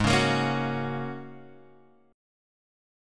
G# 9th Suspended 4th